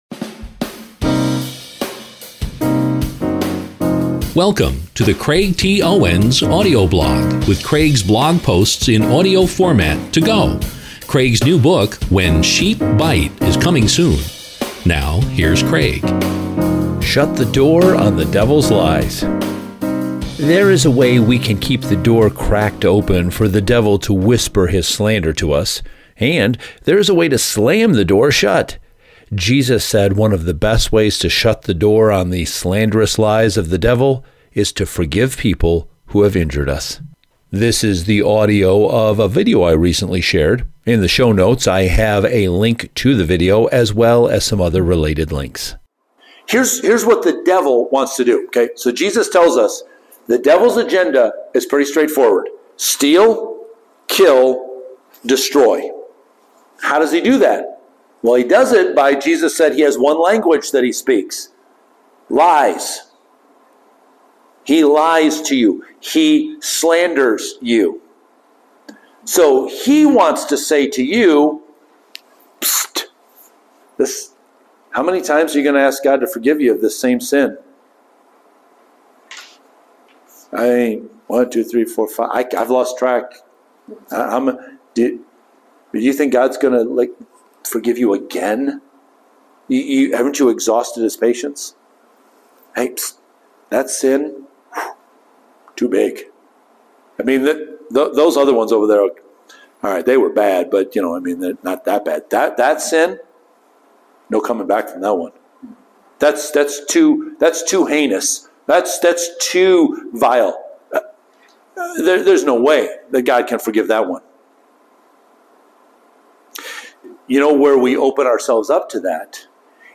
The full sermon that this clip comes from is Faith to Forgive.